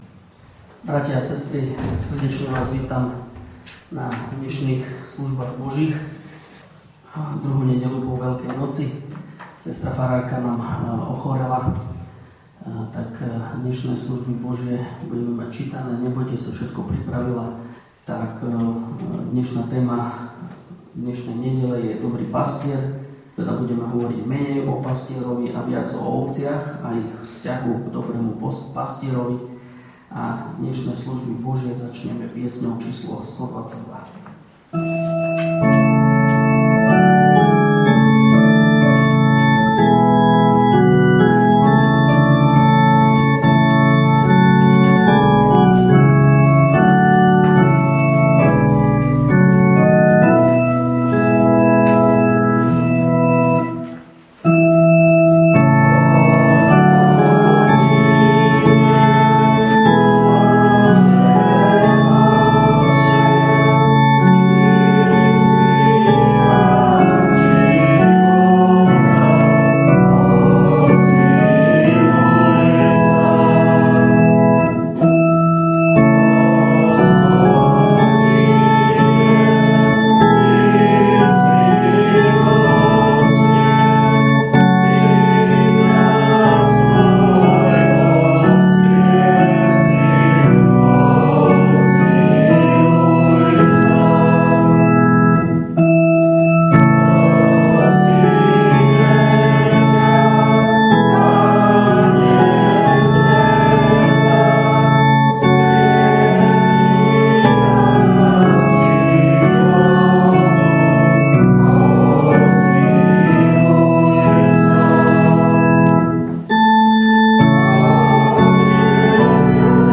V nasledovnom článku si môžete vypočuť zvukový záznam zo služieb Božích – 2. nedeľa po Veľkej noci.